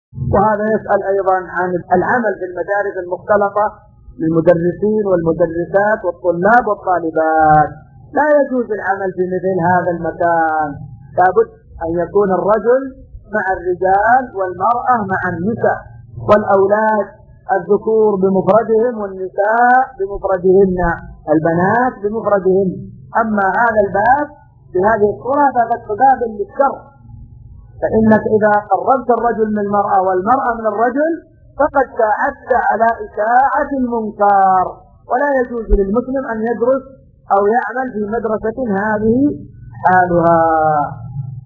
(سؤال مقتطف من شرح كتاب الصيام من زاد المستقنع).
ملف الفتوي الصوتي عدد الملفات المرفوعه : 1